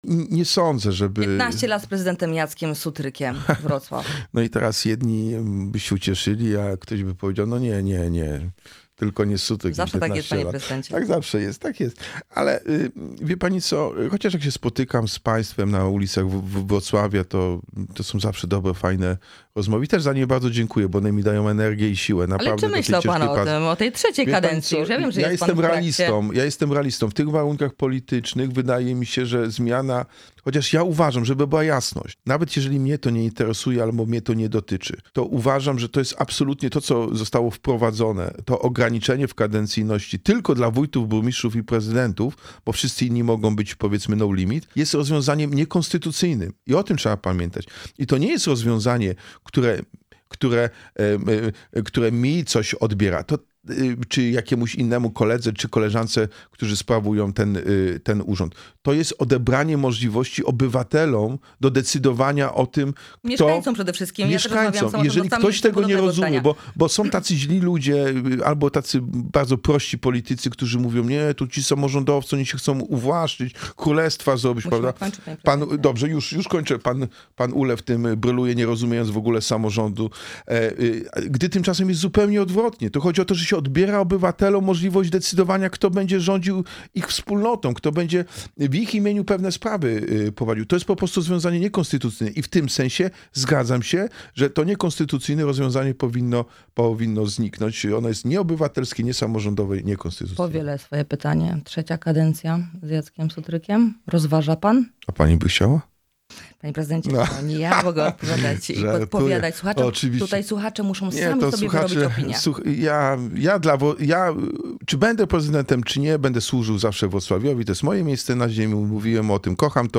Jacek Sutryk – prezydent Wrocławia był naszym gościem.